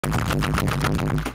PLAY BAM BOOM BANG!
bang20bang.mp3